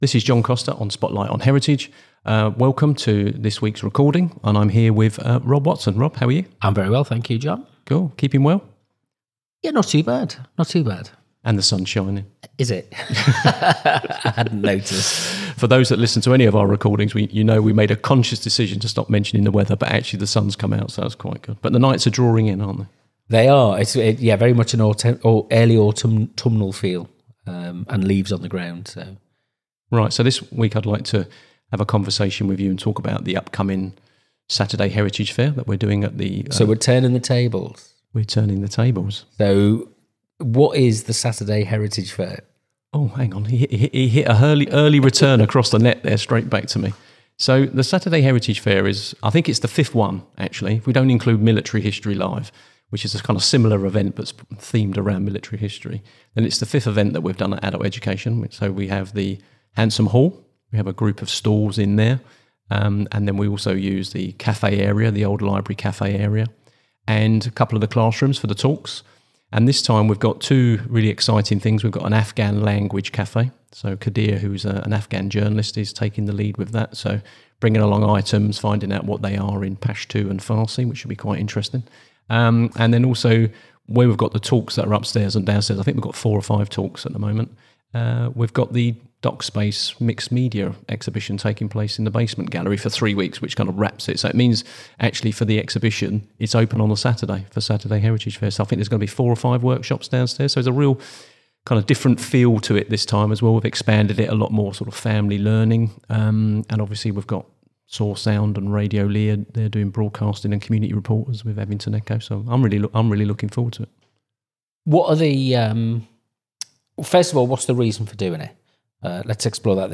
The conversation explores what it means to think about heritage in an independent way, outside of the official frameworks of museums and institutions.